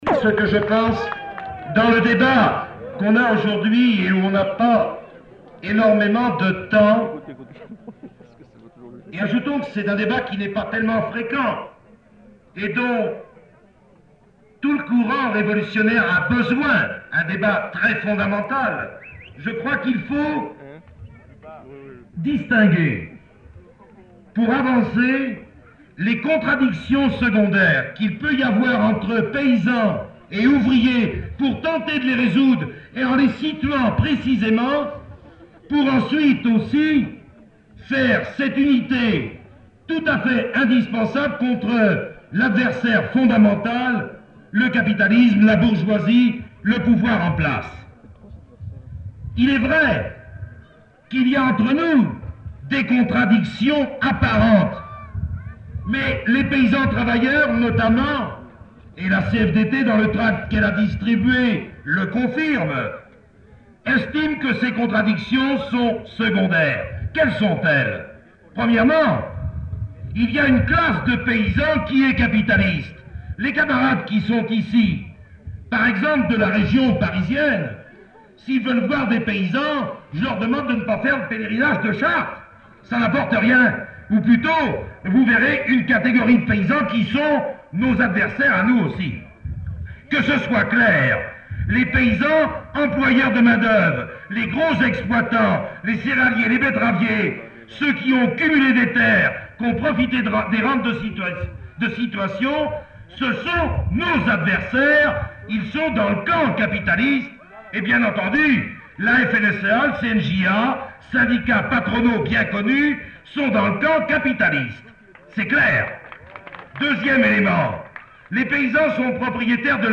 Lieu : [sans lieu] ; Aveyron
Genre : parole